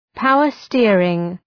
{‘paʋər,stırıŋ}